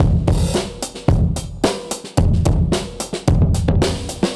Index of /musicradar/dusty-funk-samples/Beats/110bpm/Alt Sound
DF_BeatA[dustier]_110-02.wav